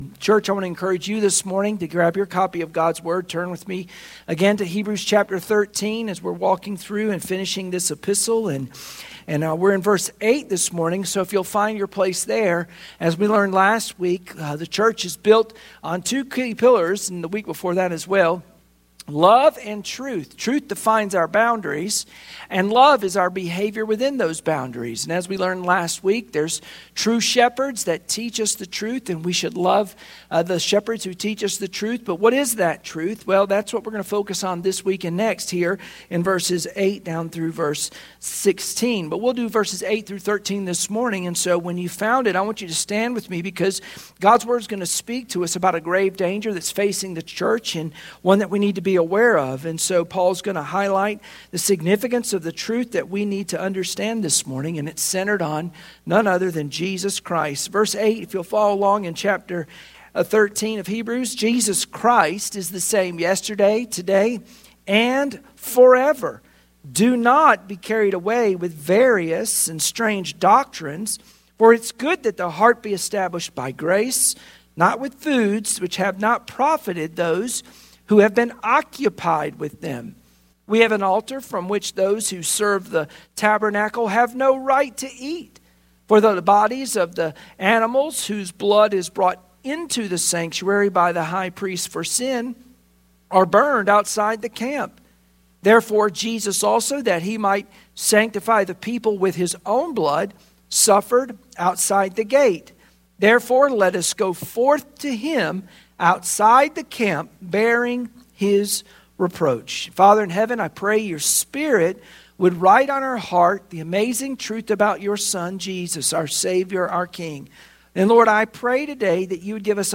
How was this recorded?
Sunday Morning Worship Passage: Hebrews 13:8-13 Service Type: Sunday Morning Worship Share this